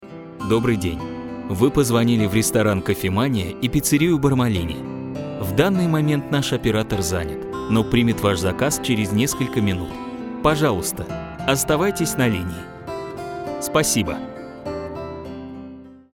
Что делали: голосовое приветствие ресторана.
Работали полный цикл: подбирали дикторов, записывали, делали музыкальное оформление, монтировали и сводили финальные ролики.
Голосовое приветствие для ресторана «Кофемания» и пиццерии «Бармалини»